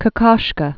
(kə-kôshkə), Oskar 1886-1980.